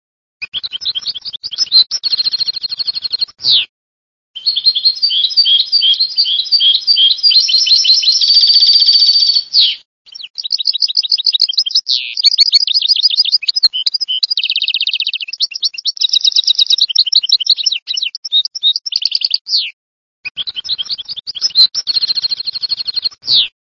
Canti degli uccelli
CARDELLINO-GOLDFINCH-CHARDONNERET-STIEGLITZ.mp3